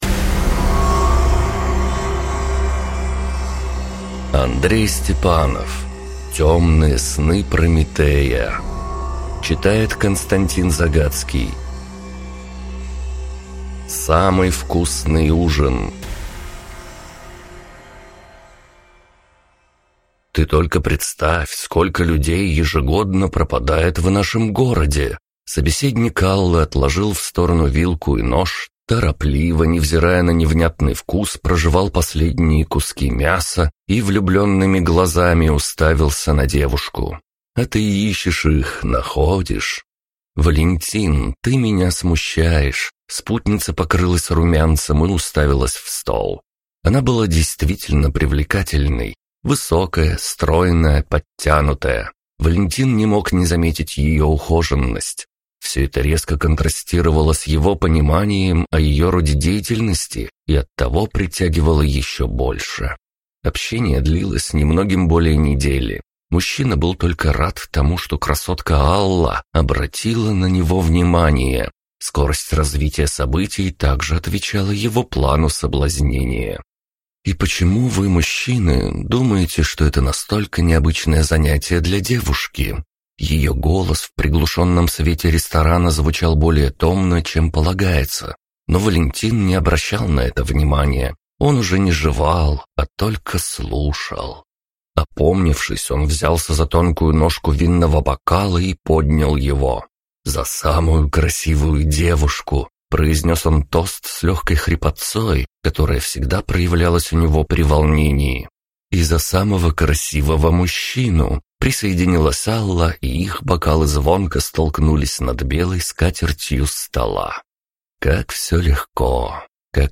Аудиокнига Темные сны Прометея | Библиотека аудиокниг